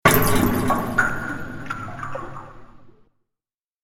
دانلود آهنگ آب 40 از افکت صوتی طبیعت و محیط
دانلود صدای آب 40 از ساعد نیوز با لینک مستقیم و کیفیت بالا
جلوه های صوتی